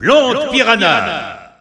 Announcer pronouncing Piranha Plant's name in French (PAL).
Piranha_Plant_French_EU_Announcer_SSBU.wav